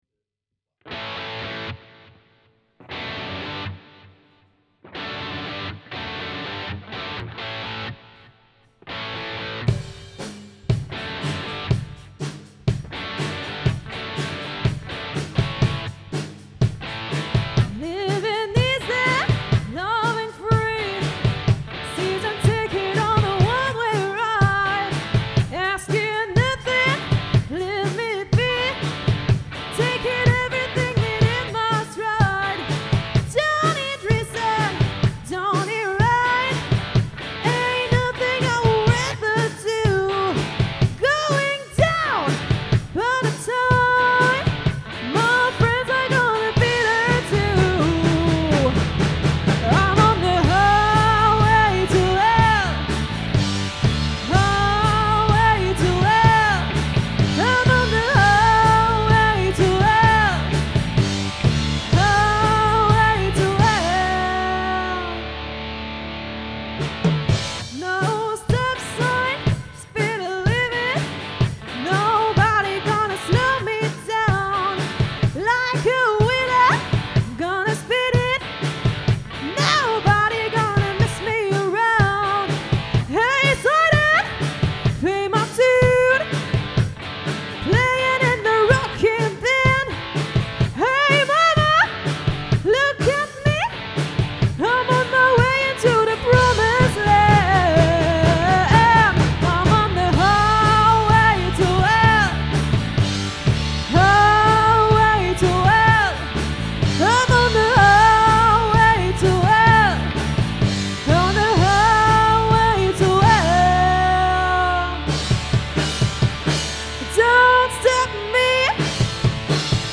le groupe Hard-rock et Heavy-metal basé à Sarreguemines.